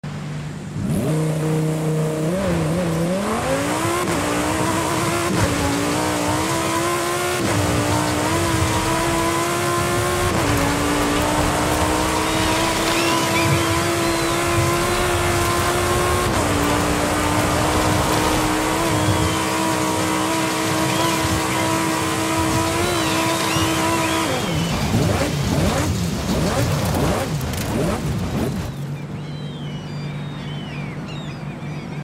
2018 Lamborghini Huracan Performante Off Road Sound Effects Free Download